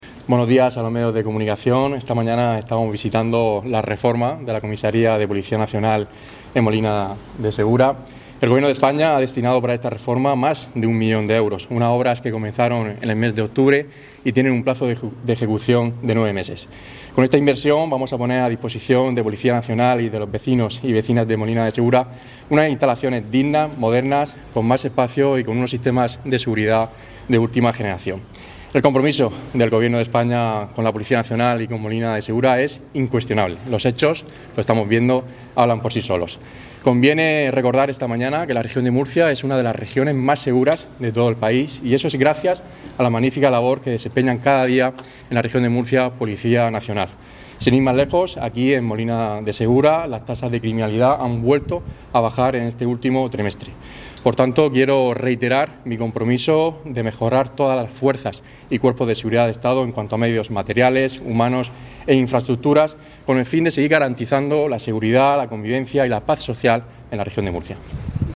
Declaraciones de Francisco Lucas